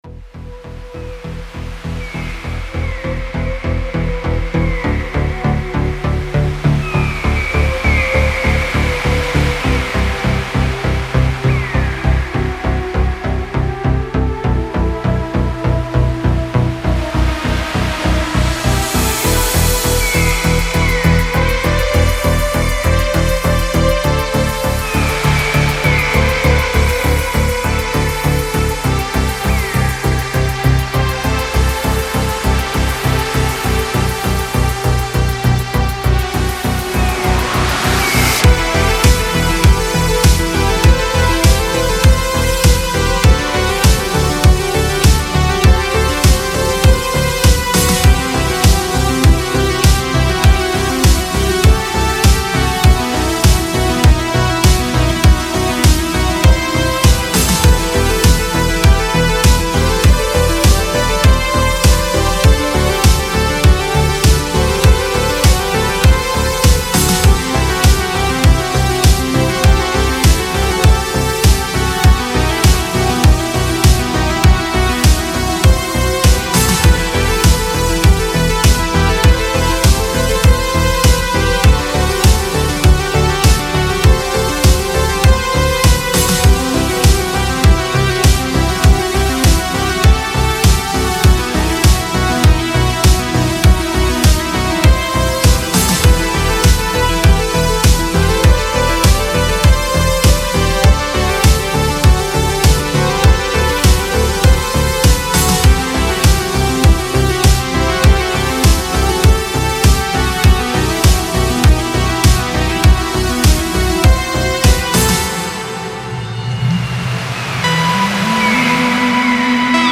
Genre: Synth Pop.